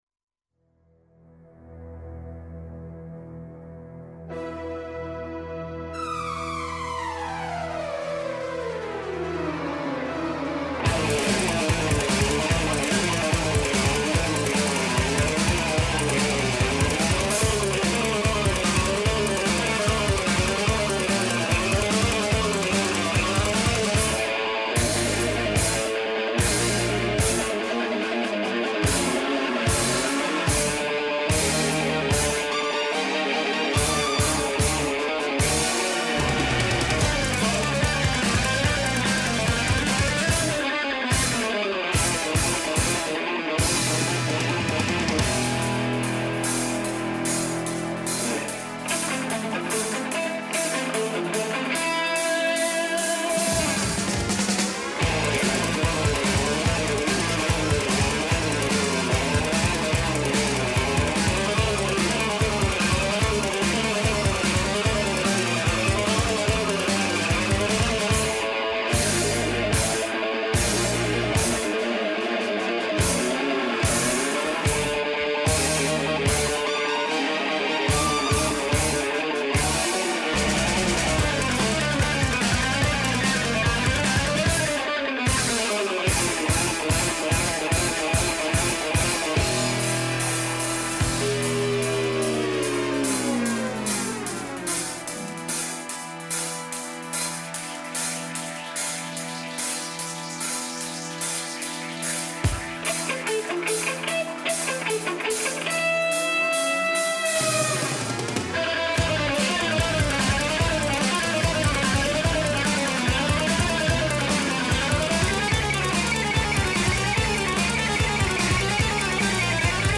A Motoros hang r�di�ban, a Rockop�dia m�sor keret�n bel�l